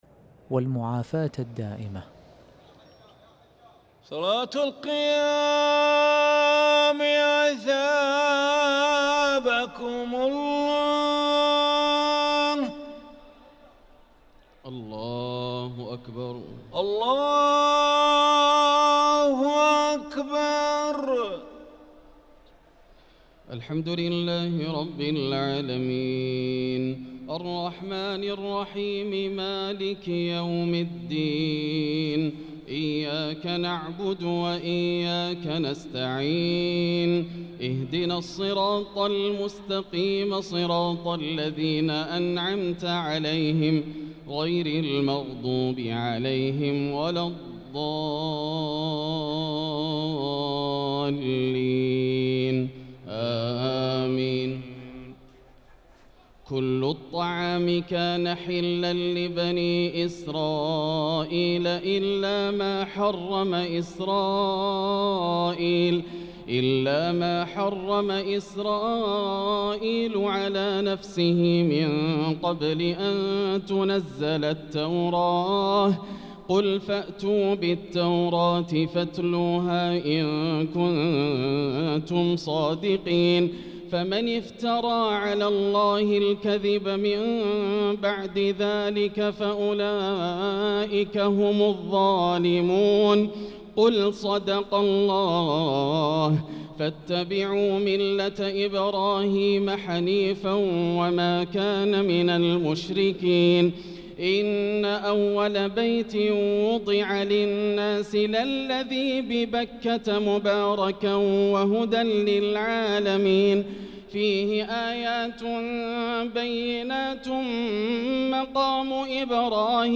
صلاة التراويح ليلة 5 رمضان 1445 للقارئ ياسر الدوسري - الثلاث التسليمات الأولى صلاة التراويح